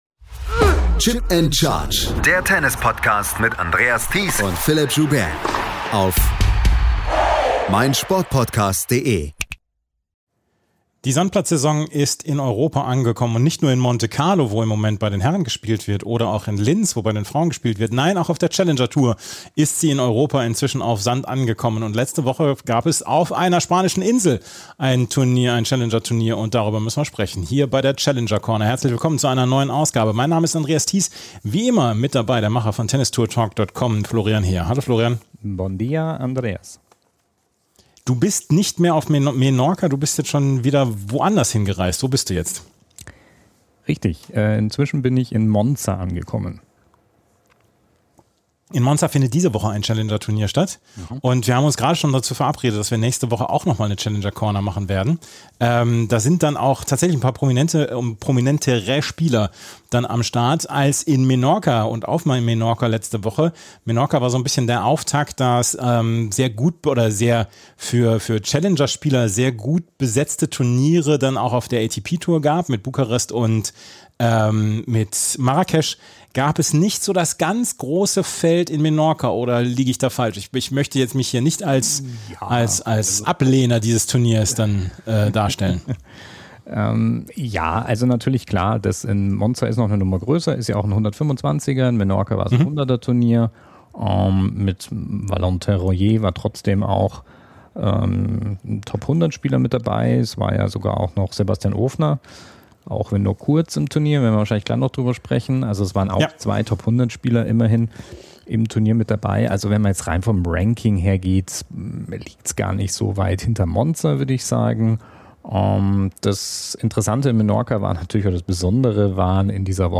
war vor Ort und hat mit den Protagonisten gesprochen.